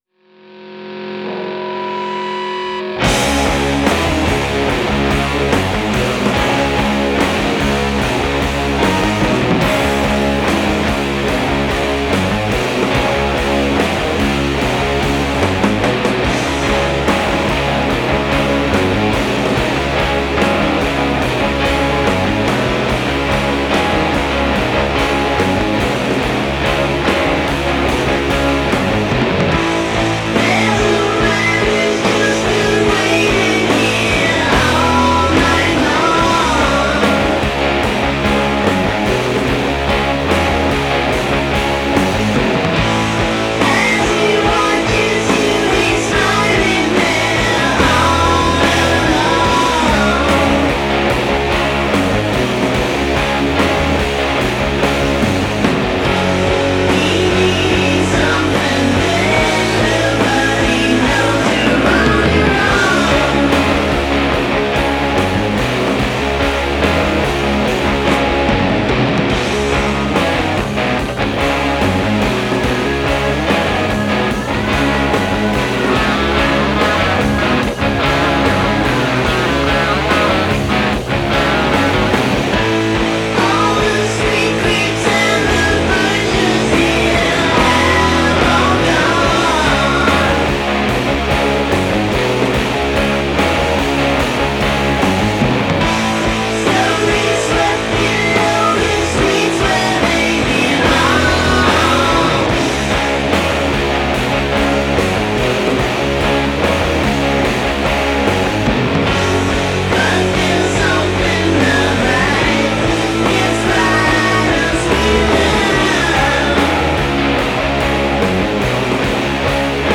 Listen closely to those vocal harmonies.
This stuff rocks hard, but it still pleases the ear.